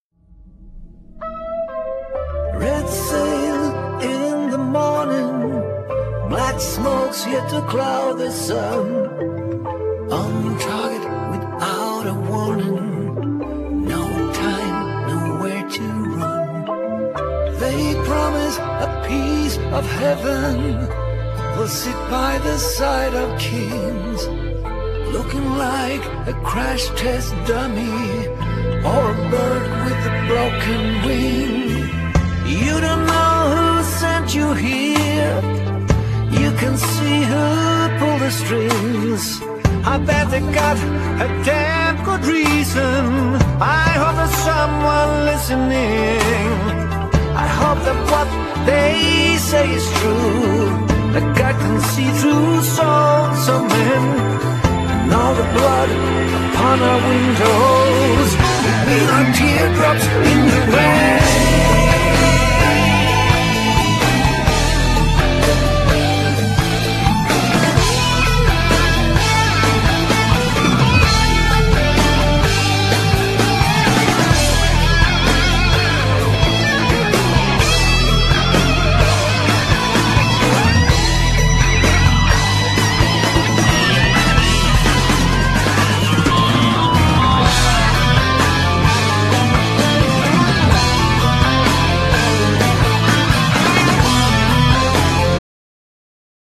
Genere : Pop rock